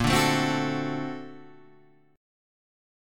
A#+M9 chord